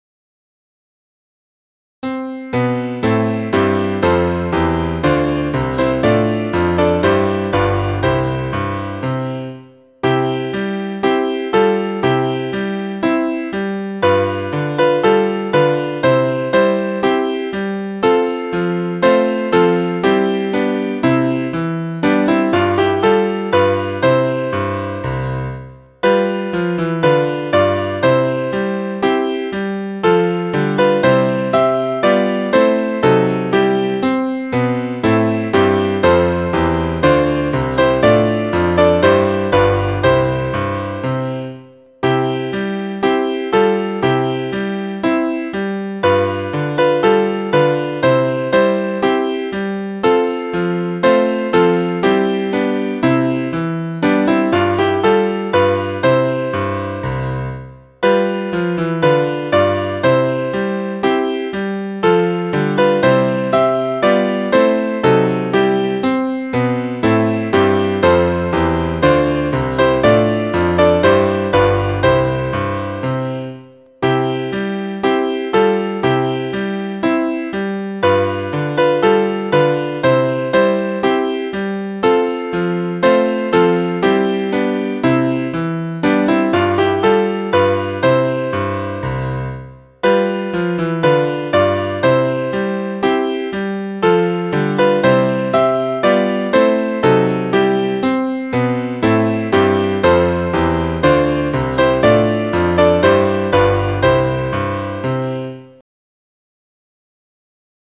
メロディーを聴くにはここをクリックしてください (音声ファイル: 2.5MB)